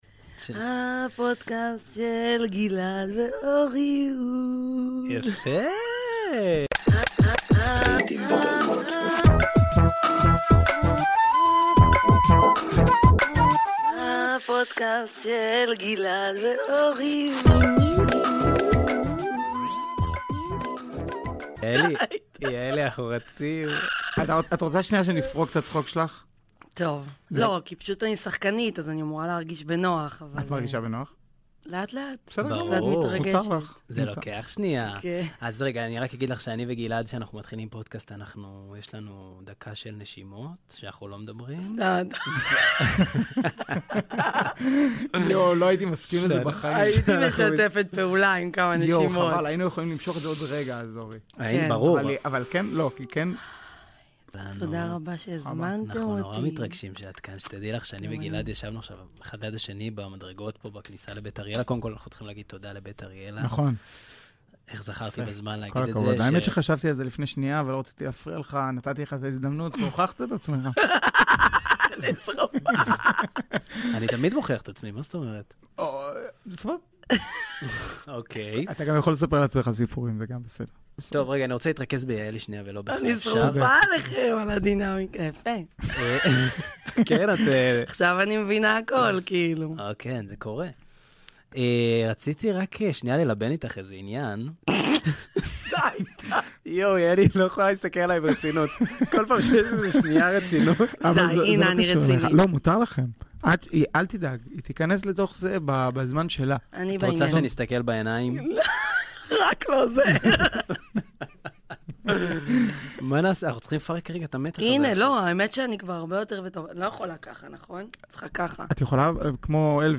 אולפן הפודקאסט של בית אריאלה